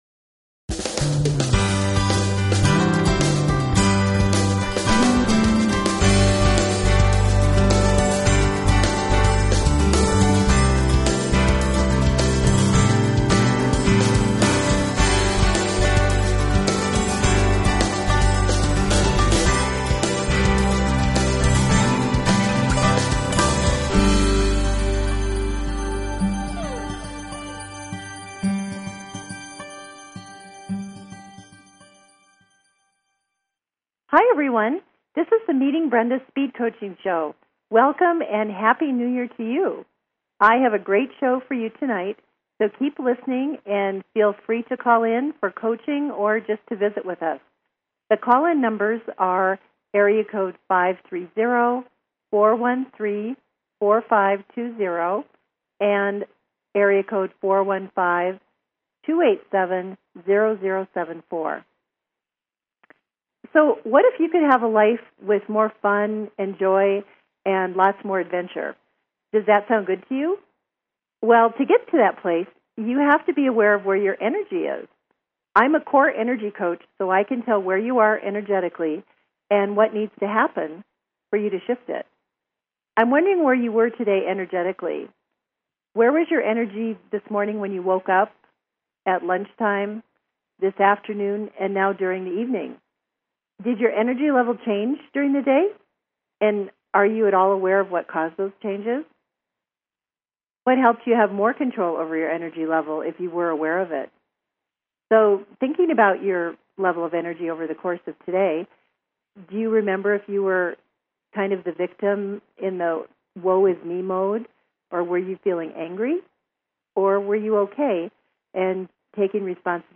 Talk Show Episode
They will be visiting the show to offer their unique perspectives.